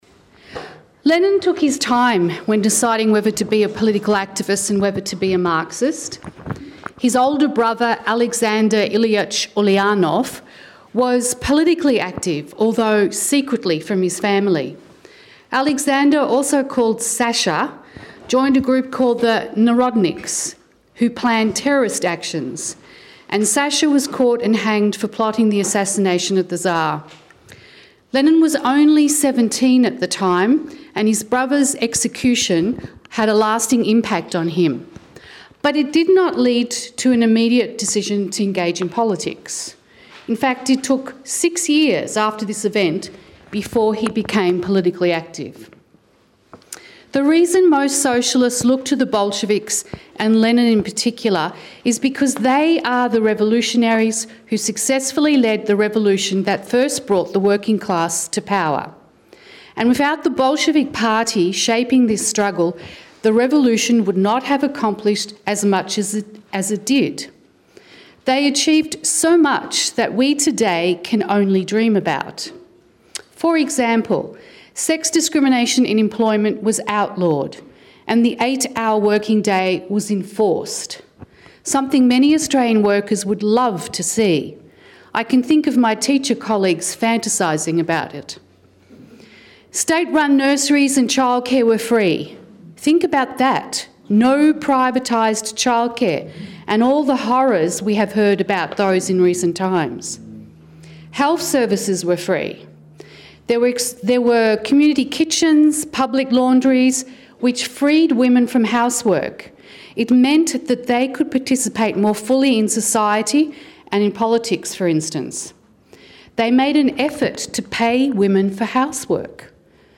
Interviews and Discussions